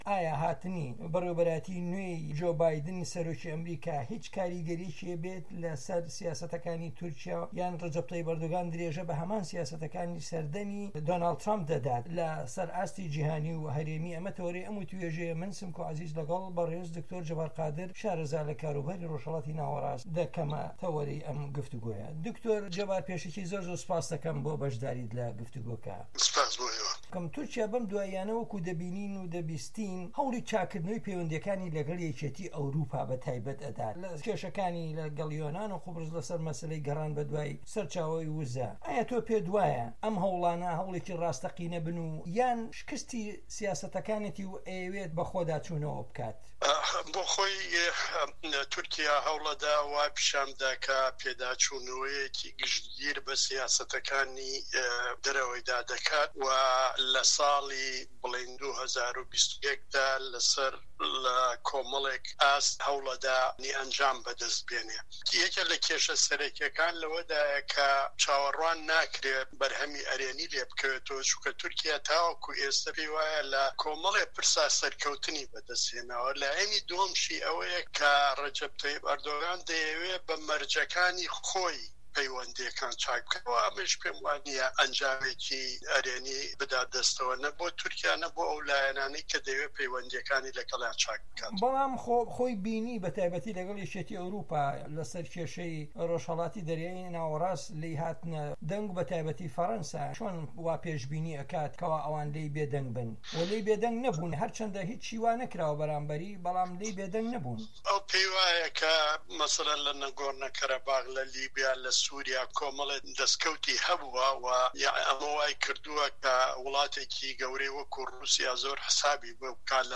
تورکیا - گفتوگۆکان